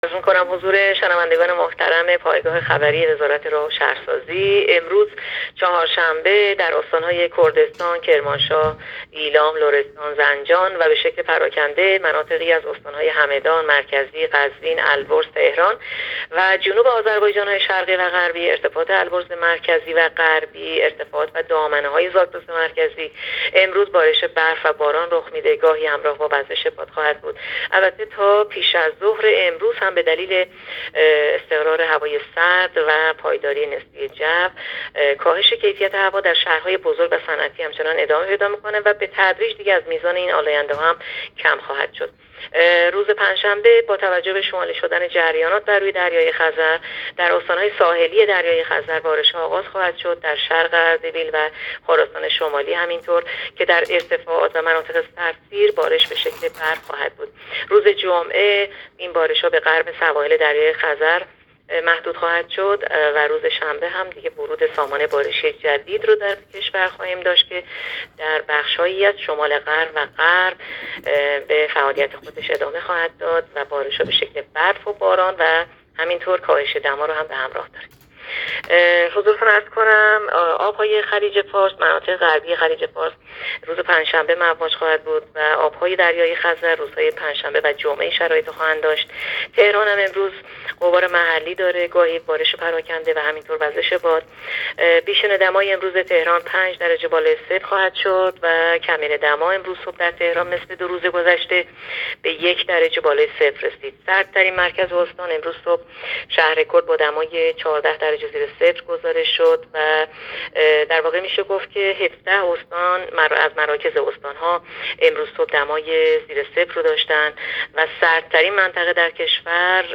گزارش رادیو اینترنتی از آخرین وضعیت آب و هوای سوم دی؛